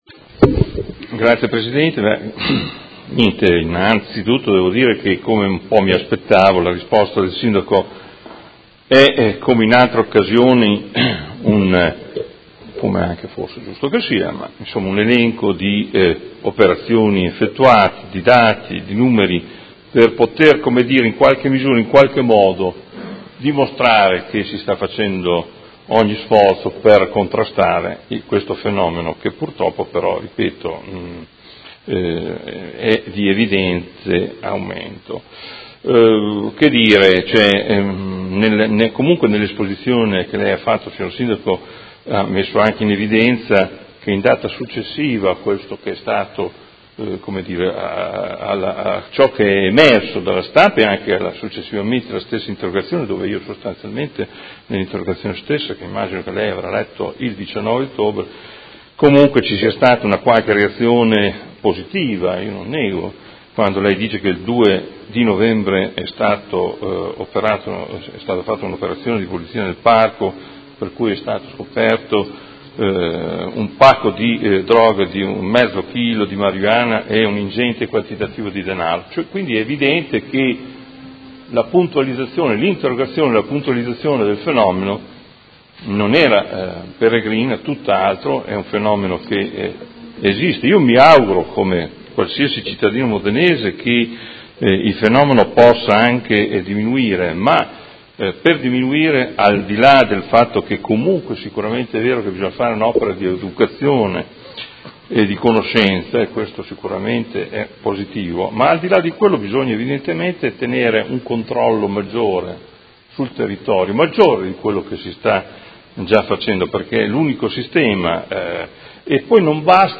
Seduta del 23/11/2017 Replica a risposta Sindaco. Interrogazione del Consigliere Morandi (FI) avente per oggetto: Lo “spaccio” della droga a Modena; un fenomeno che sta aumentando a danno soprattutto dei giovani che sempre più vengono avvicinati e convinti allo “sballo” facile con l’utilizzo di droga